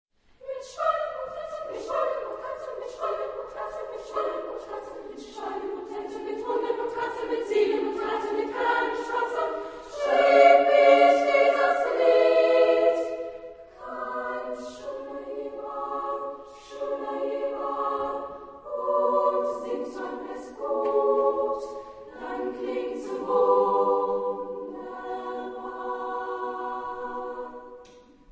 Género/Estilo/Forma: Canto coral ; Canon ; Profano
Tipo de formación coral: SSAA  (4 voces Coro infantil )
Tonalidad : libre